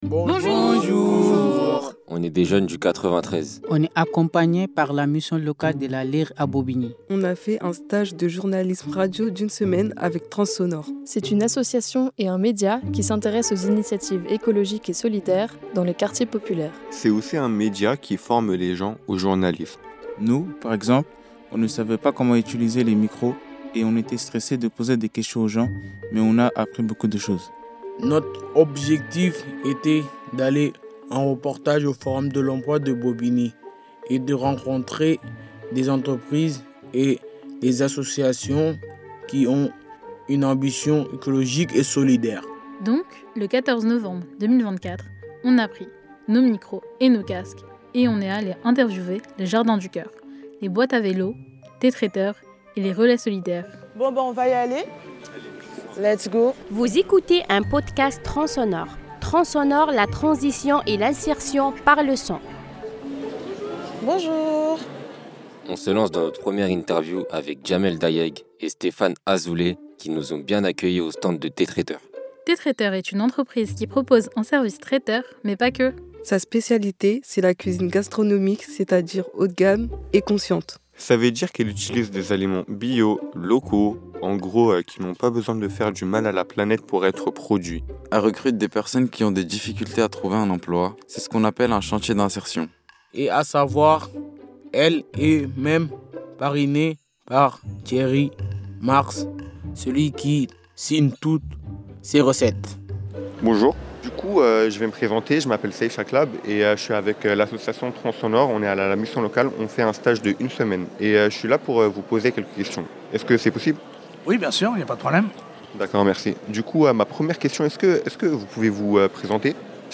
Bonjour ! On est des jeunes du 93.
Notre objectif était d’aller en reportage au forum de l’emploi de Bobigny et de rencontrer des entreprises et associations qui ont une ambition écologique et solidaire.